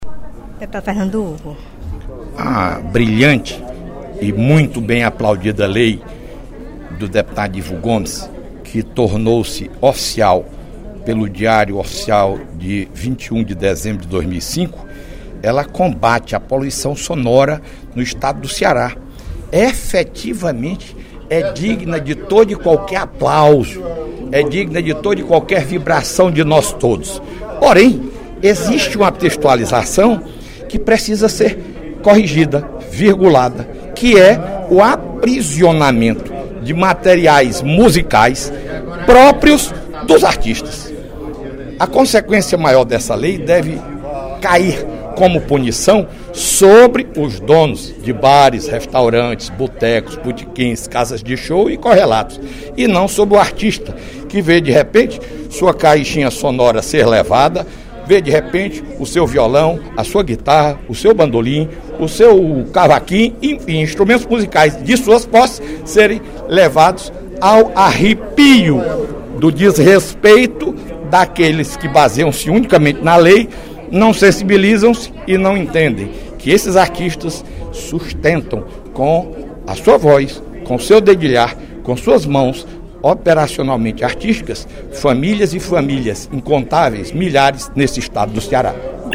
O deputado Fernando Hugo (PP) informou, nesta terça-feira (21/06) durante primeiro expediente da sessão plenária, que vai apresentar emenda à Lei do Silêncio, medida de combate “à poluição sonora gerada por estabelecimentos comerciais e por veículos” no Estado.